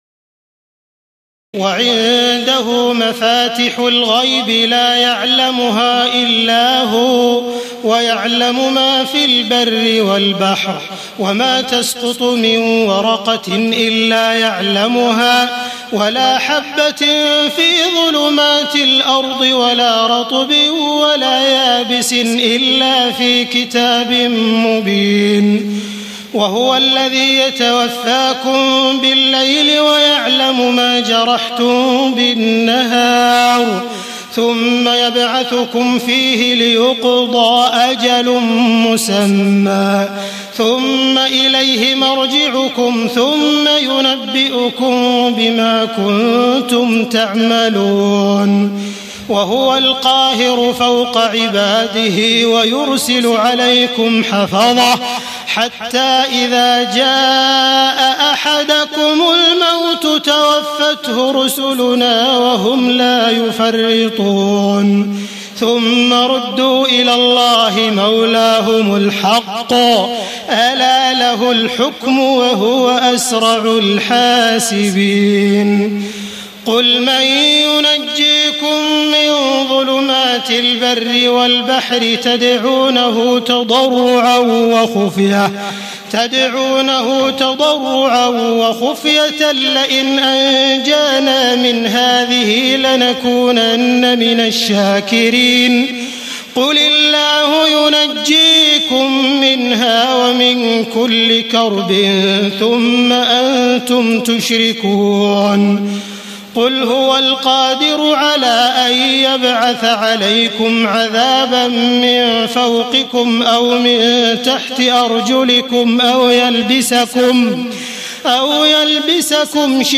تهجد ليلة 27 رمضان 1431هـ من سورة الأنعام (59-111) Tahajjud 27 st night Ramadan 1431H from Surah Al-An’aam > تراويح الحرم المكي عام 1431 🕋 > التراويح - تلاوات الحرمين